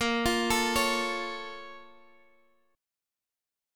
BbmM7 chord